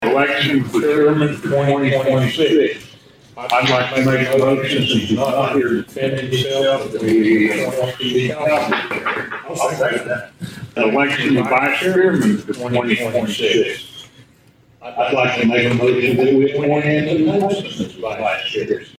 The Board of Osage County Commissioners met for the first time in 2026. With that comes the restructuring of who sits as chairman and vice-chairman of the board.
Cartwright made the following motions.